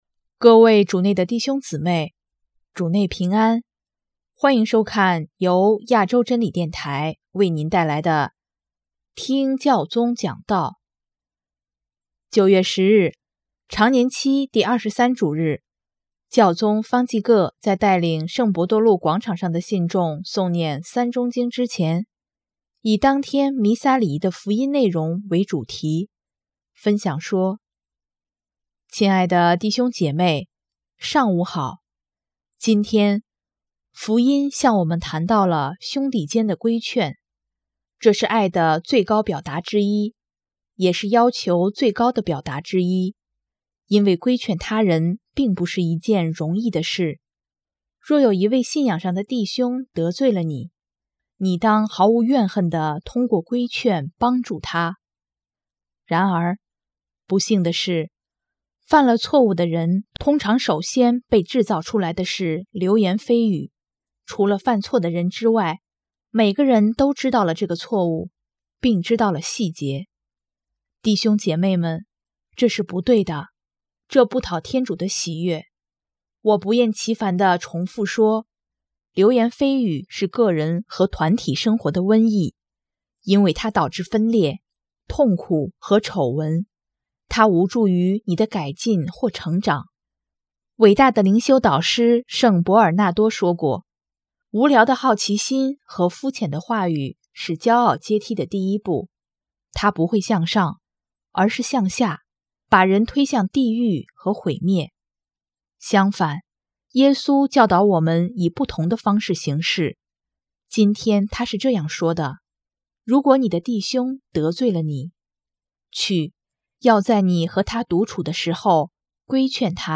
9月10日，常年期第二十三主日，教宗方济各在带领圣伯多禄广场上的信众诵念《三钟经》之前，以当天弥撒礼仪的福音内容为主题，分享说：